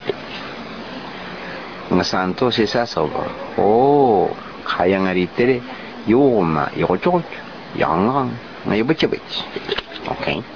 telling this true story from 1972 in Tobian